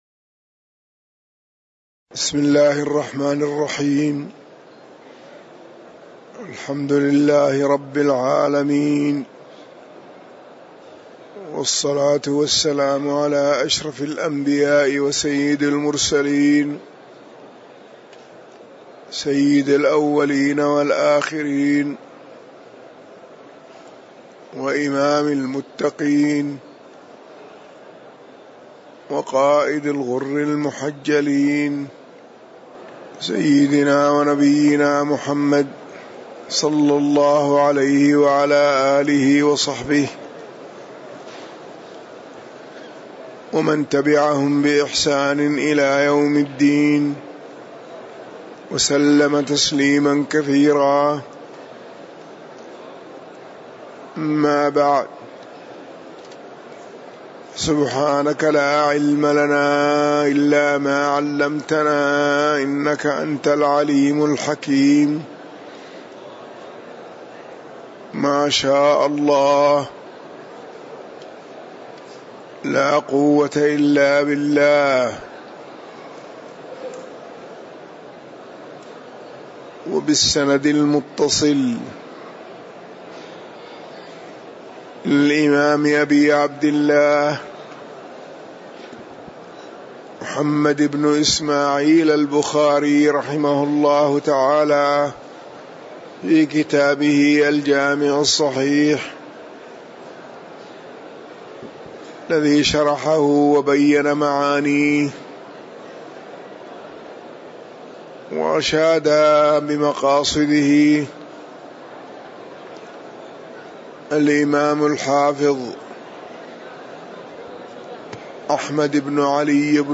تاريخ النشر ٢٣ ربيع الأول ١٤٤١ هـ المكان: المسجد النبوي الشيخ